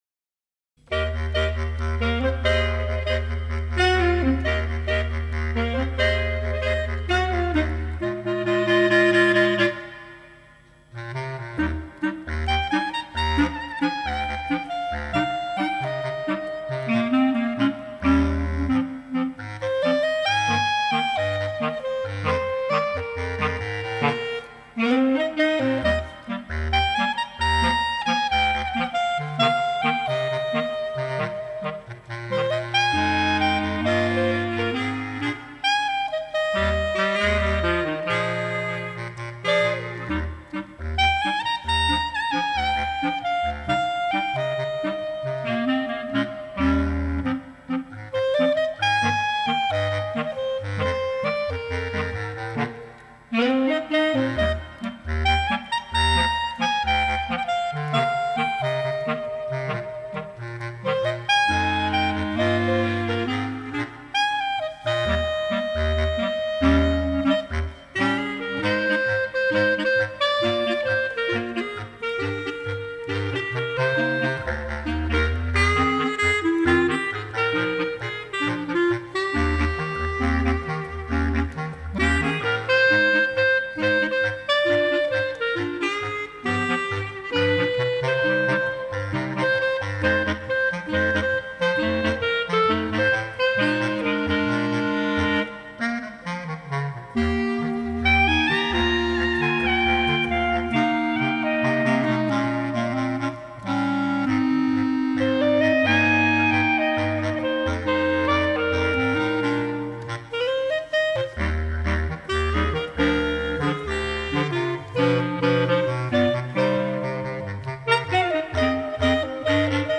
Scored for 3 Bb Clarinets and 1 Bass Clarinet